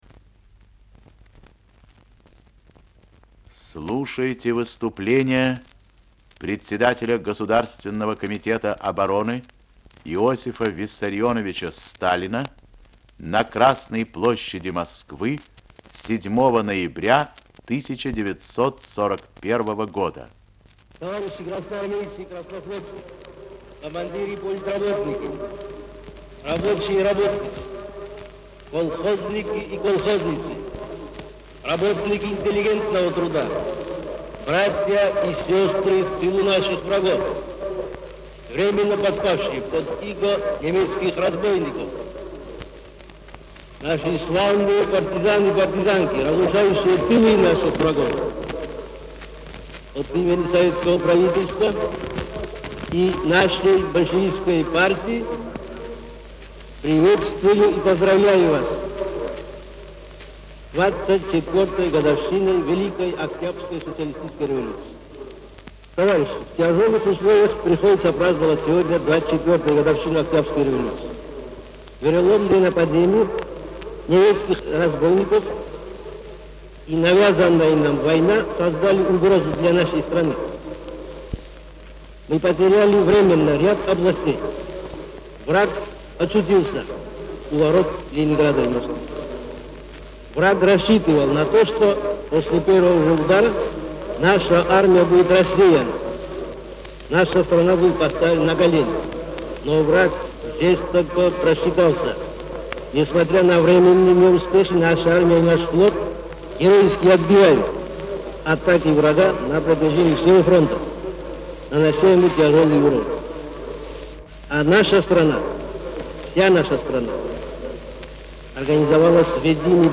Сталин И.В. речь 7.11.1941